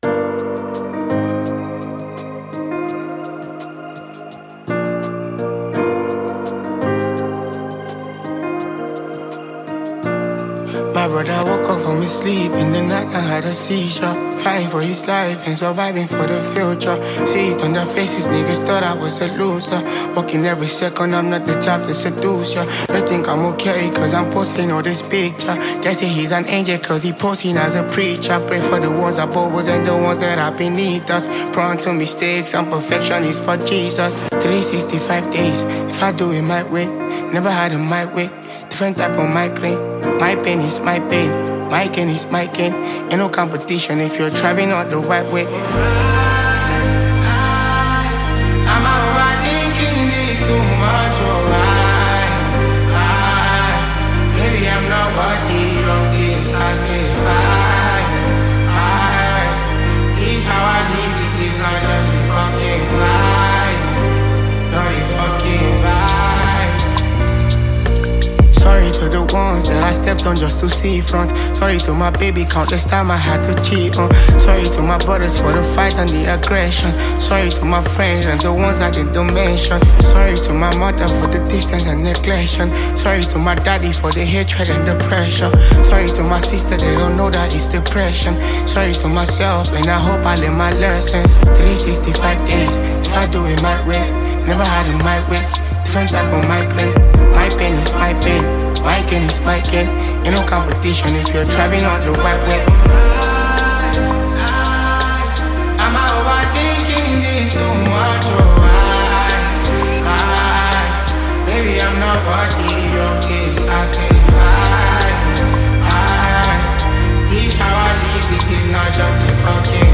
Well renowned Nigerian artist and performer
thrilling new gbedu song